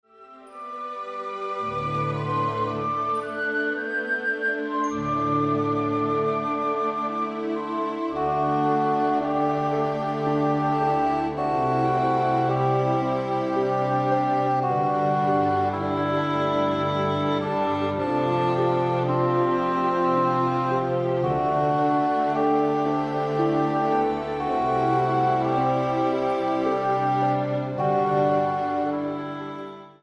Instrumentals